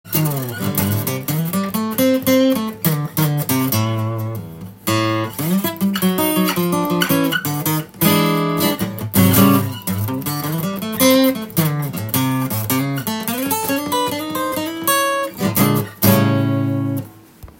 試しに弾かせて頂きました。
マーチン独特のミドルレンジとレトロな音がメイドインメキシコでも